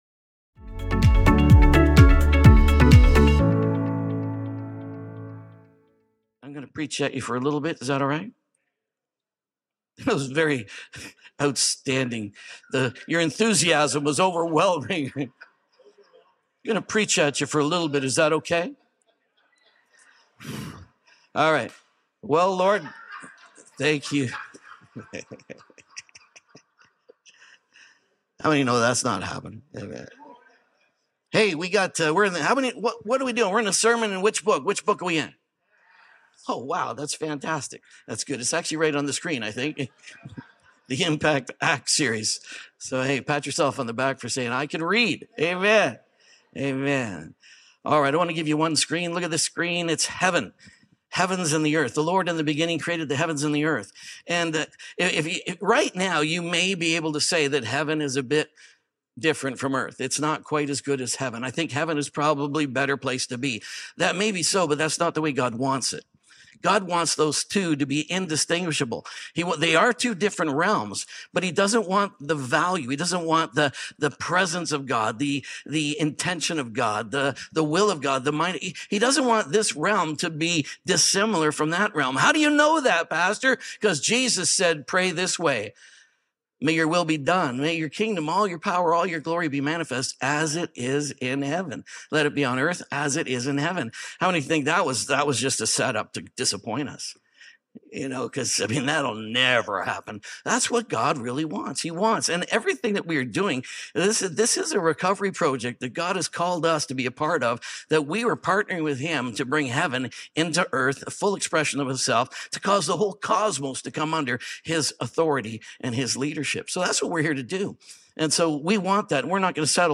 Problem Solving with Holy Spirit | DRENCH SERIES | SERMON ONLY.mp3